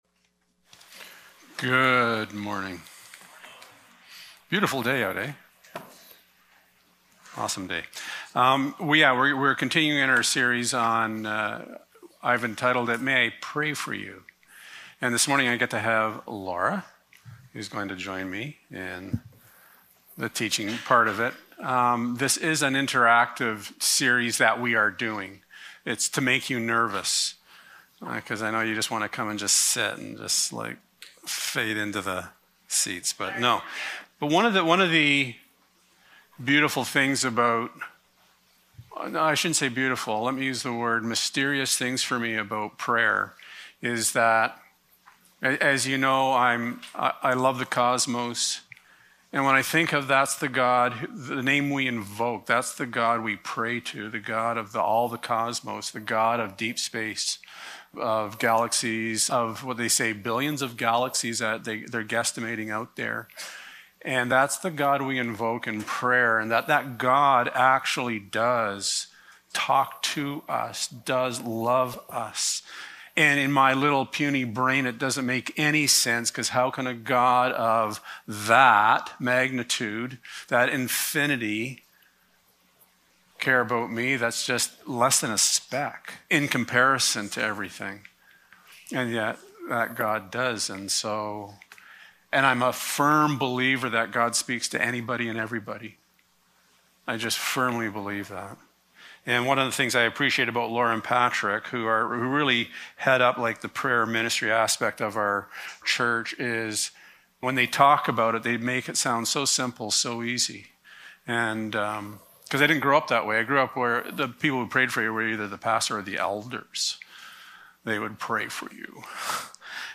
Passage: John 3: 27-30 Service Type: Sunday Morning The interesting thing about servants is that you know they are doing an excellent job when they are not noticed.